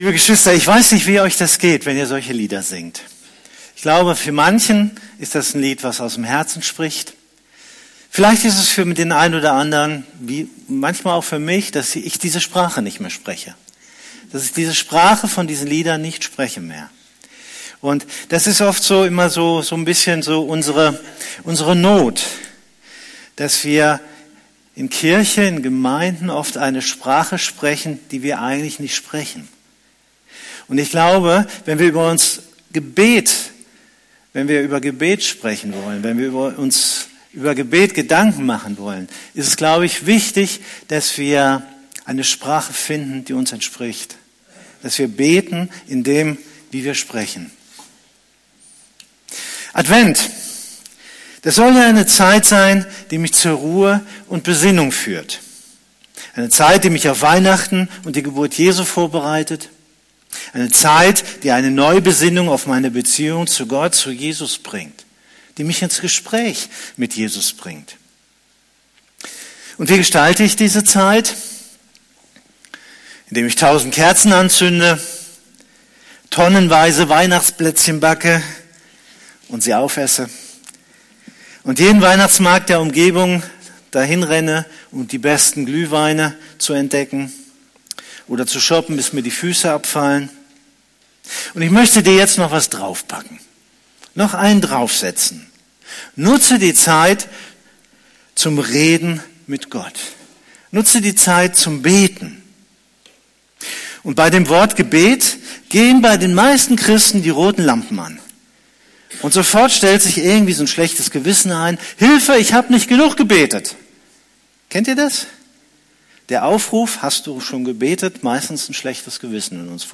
Predigt
predigt.mp3